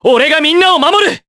Kasel-Vox_Skill5_jp.wav